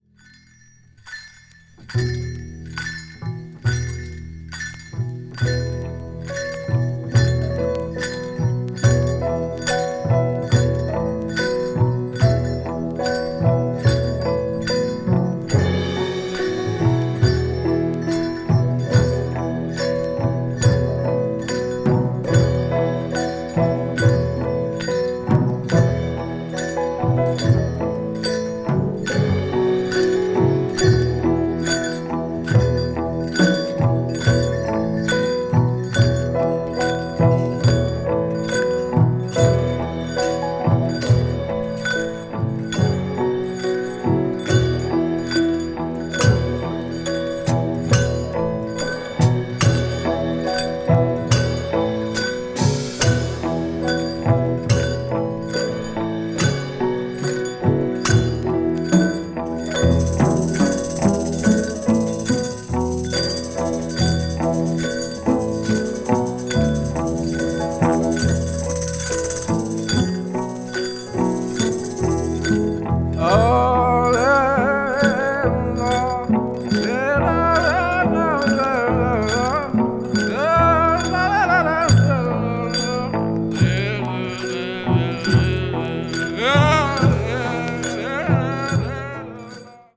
provides a pleasant atmosphere.
avant-jazz   free improvisaton   free jazz   spiritual jazz